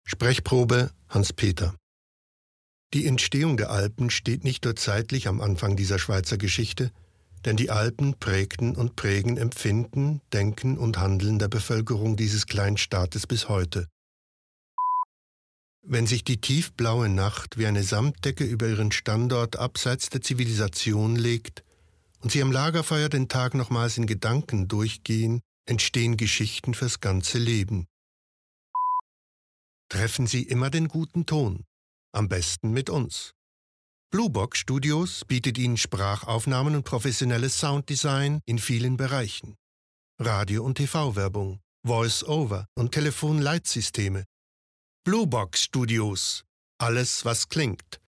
OFF-Kommentar Hochdeutsch (CH)
Sprecher mit breitem Einsatzspektrum.